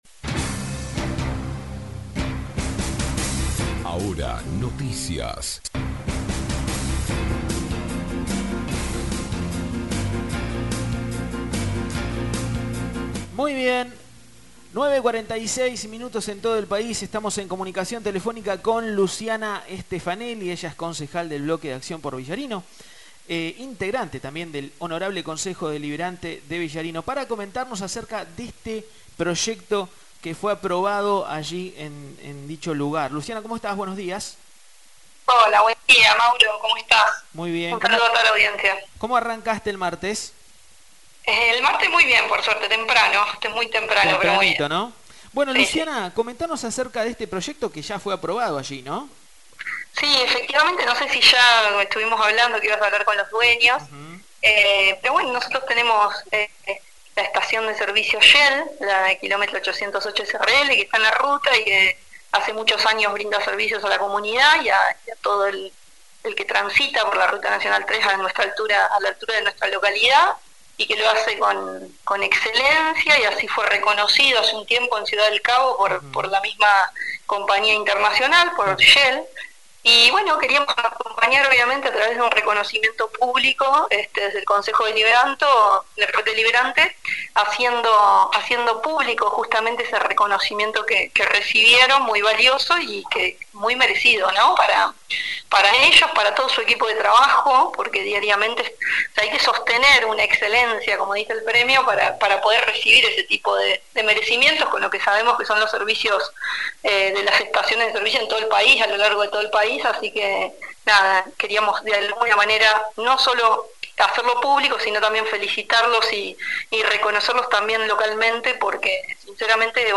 Escuchá la nota en Realizada a la Concejal del Bloque Acción Por Villarino Luciana Stefanelli a continuación